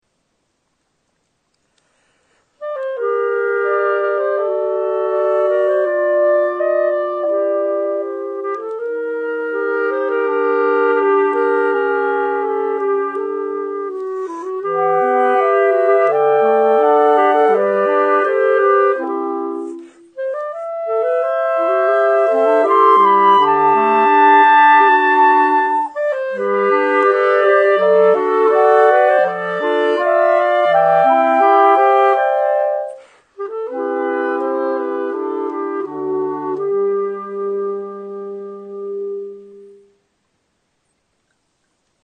Klarinet